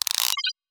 gearright.wav